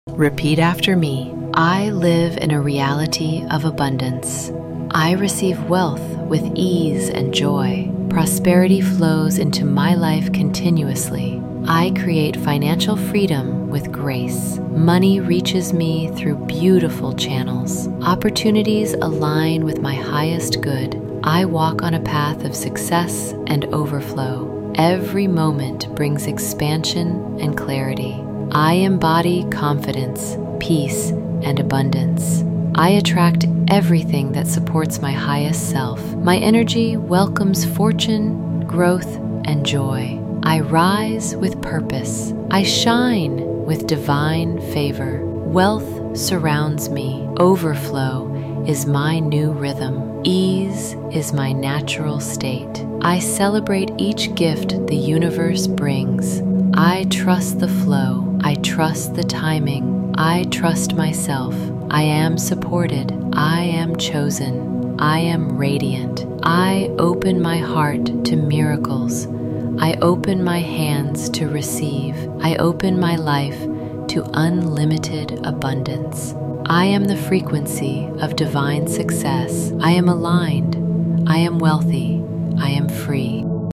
This audio will help you cut ties with bad luck, scarcity, and financial stress. 888 Hz = the frequency of infinite abundance and aligned prosperity. Repeat these affirmations to reprogram your energy, your mindset, and your vibration.